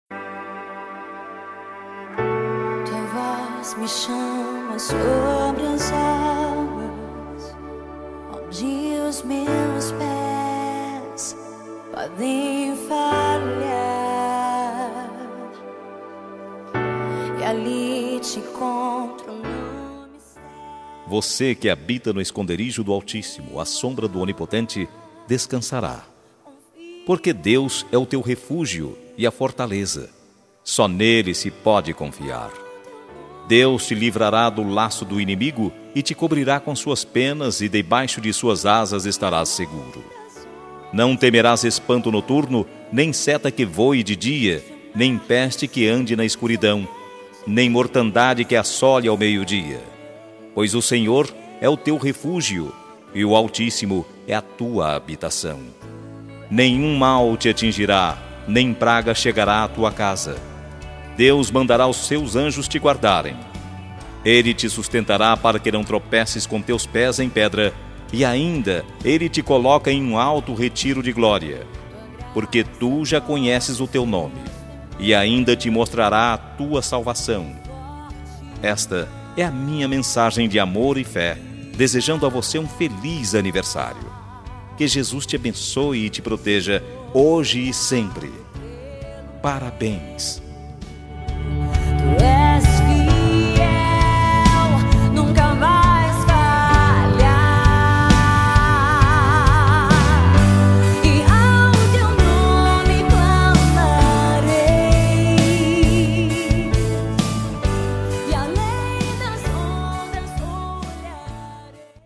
Voz Masculina
Código: 040406 – Música: Oceanos – Artista: Ana Nobrega